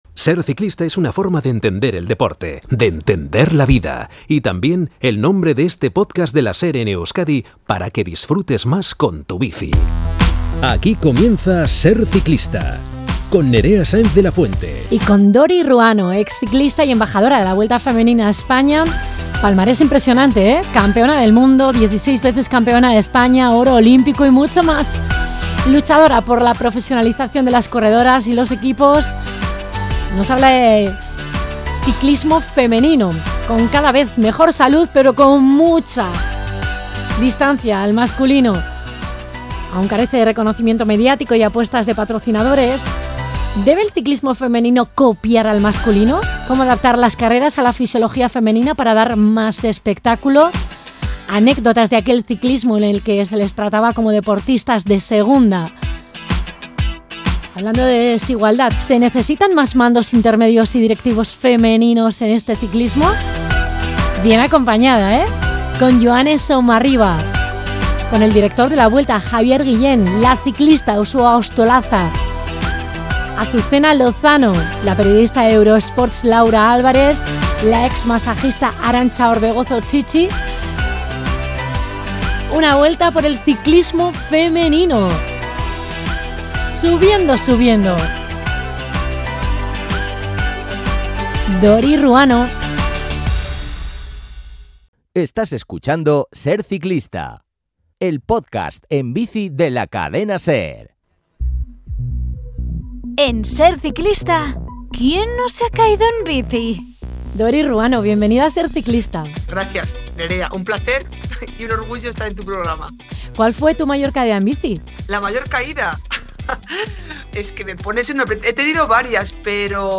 Entrevista a Dori Ruano, ex ciclista y Embajadora de la Vuelta Femenina a España, que cuenta con un palmarés impresionante: Campeona del Mundo y 16 veces Campeona de España , y un Oro olímpico.